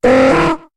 Cri de Bekipan dans Pokémon HOME.